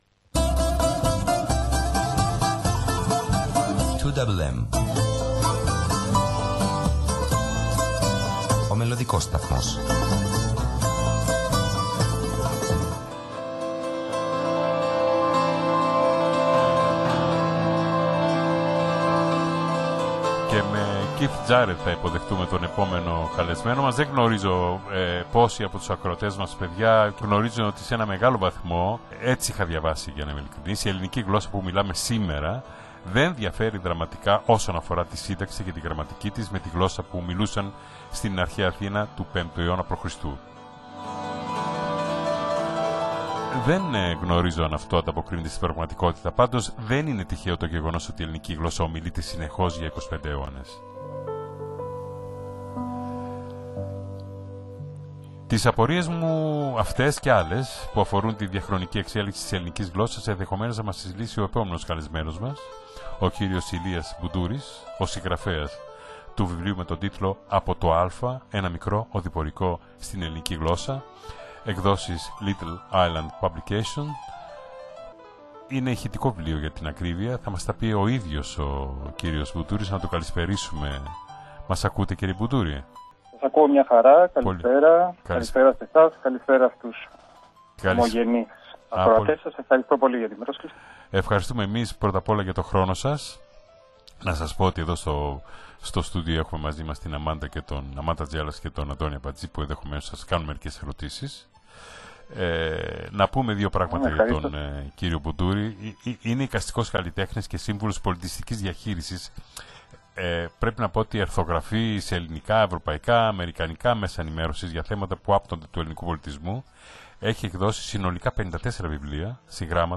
μίλησε ζωντανά